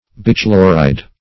Bichloride \Bi*chlo"ride\, n. [Pref. bi- + chloride.] (Chem.)